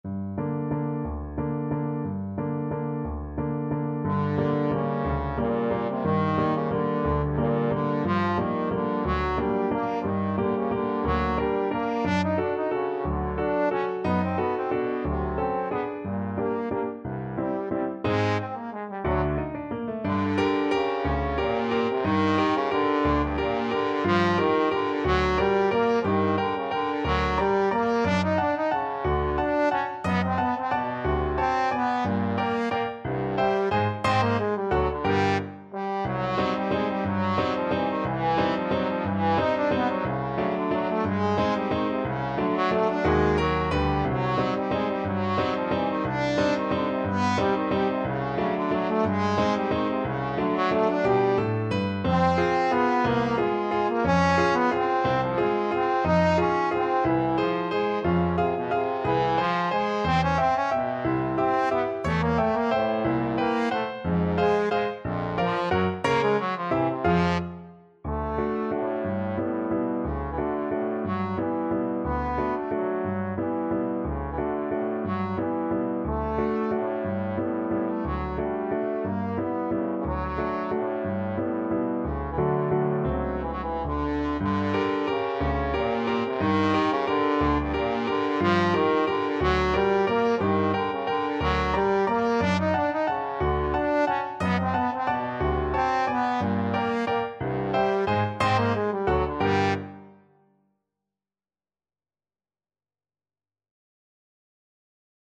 Trombone version
3/4 (View more 3/4 Music)
Allegro espressivo .=60 (View more music marked Allegro)
Classical (View more Classical Trombone Music)